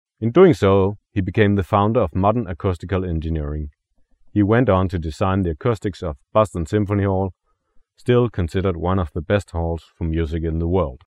Genre : Blues Rédiger un commentaire Annuler la réponse Vous devez vous connecter pour publier un commentaire.